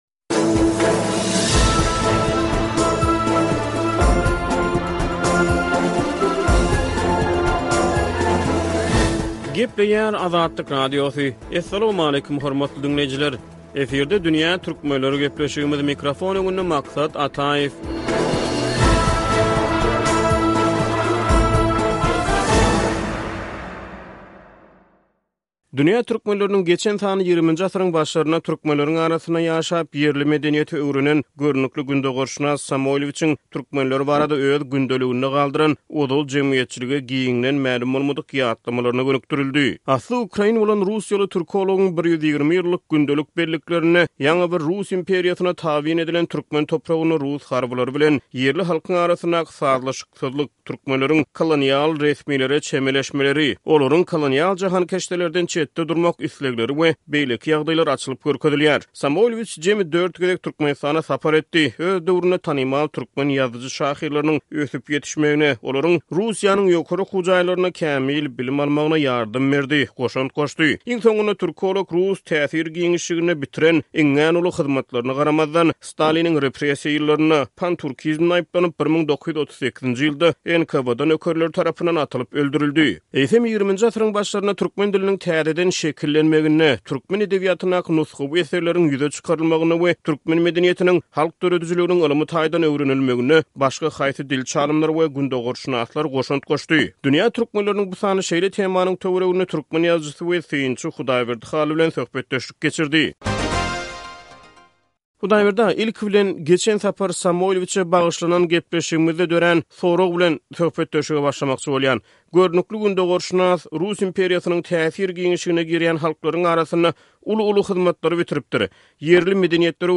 Dünýä Türkmenleriniň bu sany XX asyryň başlarynda türkmen diliniň täzeden şekillenmegine, türkmen edebiýatyndaky nusgawy eserleriň ýüze çykarylmagyna we türkmen medeniýetiniň, halk döredijiliginiň ylmy taýdan öwrenilmegine goşant goşan gündogarşynaslar baradaky söhbetdeşlige gönükdirilýär.